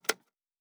pgs/Assets/Audio/Sci-Fi Sounds/Mechanical/Device Toggle 16.wav at master
Device Toggle 16.wav